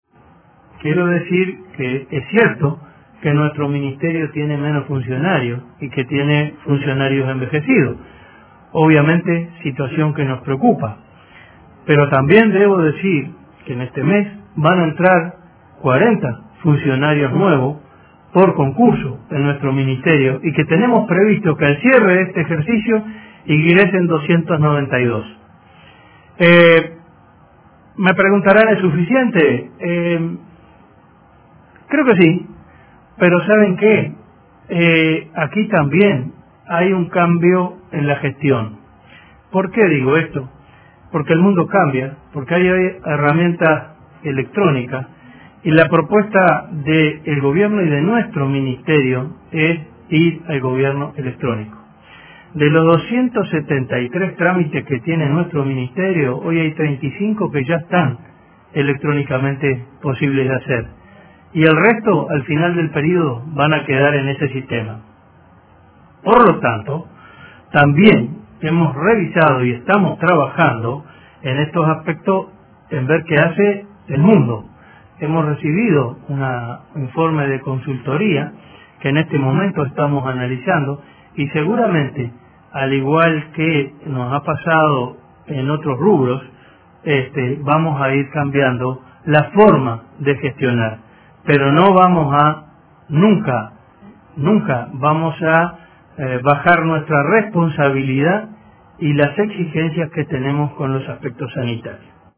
“Permanentemente piden que achiquemos el tamaño del Estado, pero a la vez critican cuando baja la cantidad de funcionarios”, afirmó el ministro de Ganadería, Enzo Benech, tras el Consejo de Ministros. Anunció que antes del final del período ingresarán 292 funcionarios por concurso y que todos los trámites serán electrónicos.